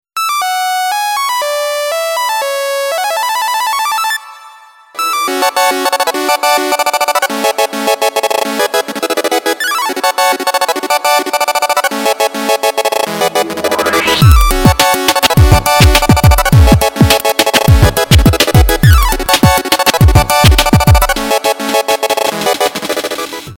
Мелодия